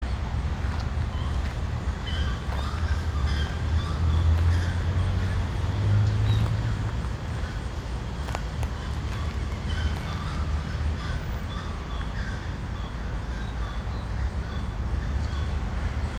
Chiricote (Aramides cajaneus)
Nombre en inglés: Grey-cowled Wood Rail
Localidad o área protegida: Florianópolis
Localización detallada: Jardín Botánico
Condición: Silvestre
Certeza: Fotografiada, Vocalización Grabada